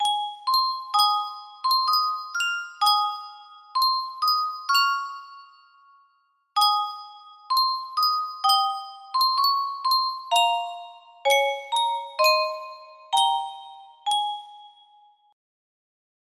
Yunsheng Music Box - Michael Row the Boat Ashore 6380 music box melody We use cookies to give you the best online experience.
BPM 64